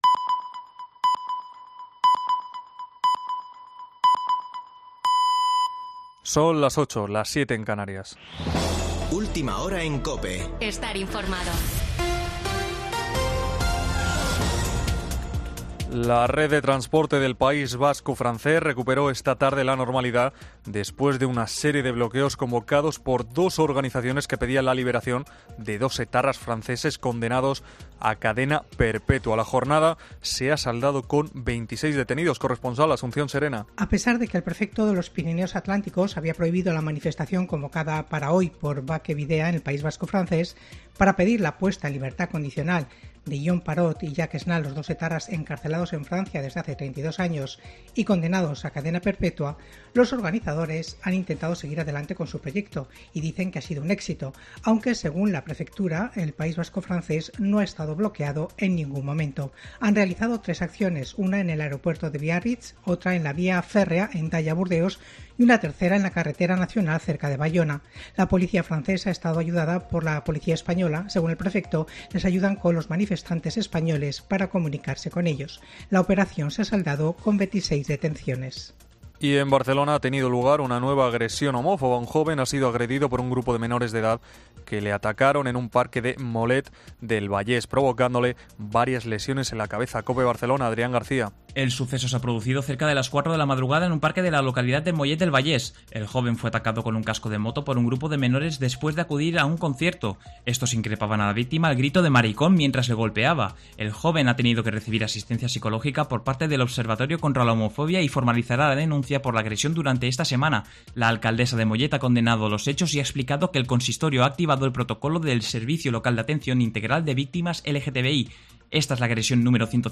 Boletín de noticias de COPE del 23 de julio de 2022 a las 20:00 horas